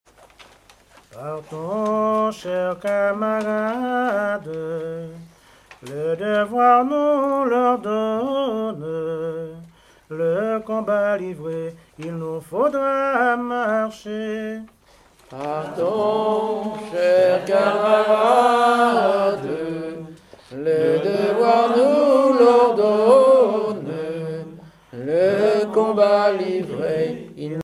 chanteur(s), chant, chanson, chansonnette
Genre strophique
chansons anciennes recueillies en Guadeloupe